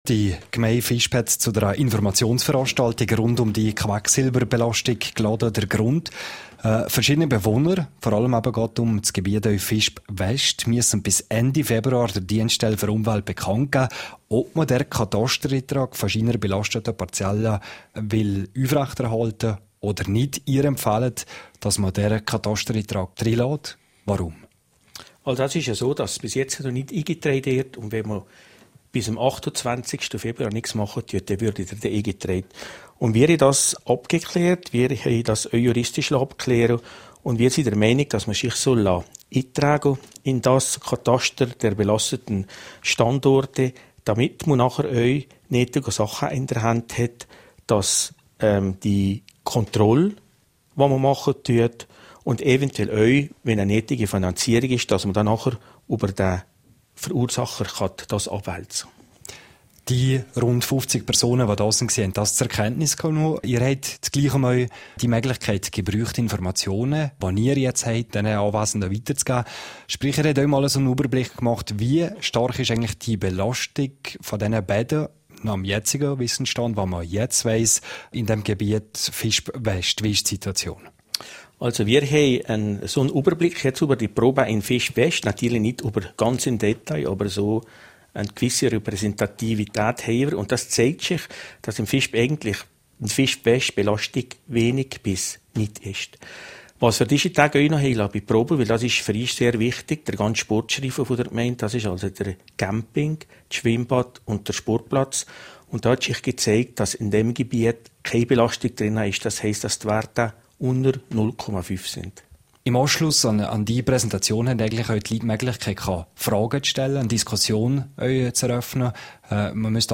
Lonza hat da eine Vorfinanzierung versprochen./kb Interview mit Gemeindepräsident Niklaus Furger (Quelle: rro)